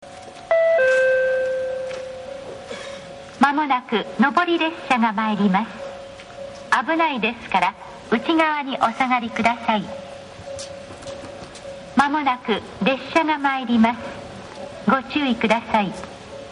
susono1sekkin.mp3